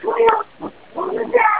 Guest EVPs
We're not sure about the first part but the last part seems to say "Mom & Dad". Some people have sensed the spirits of two young children in the resturant.